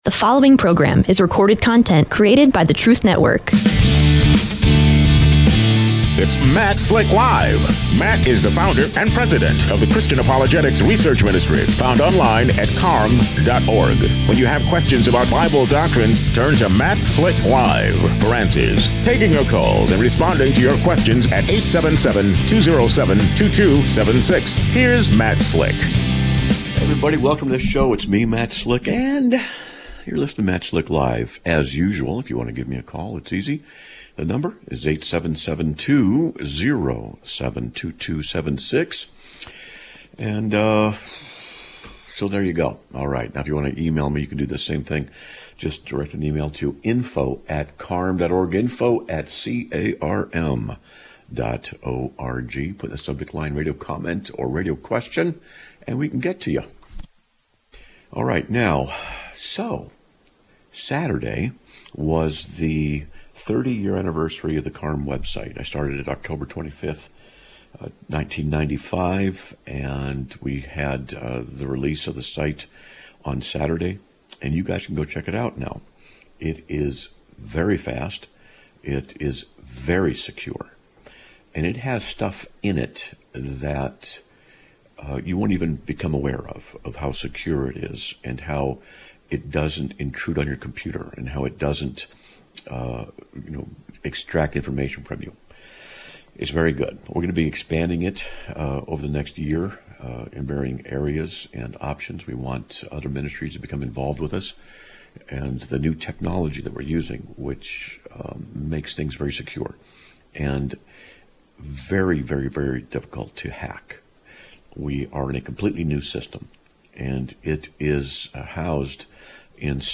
Live Broadcast of 10/27/2025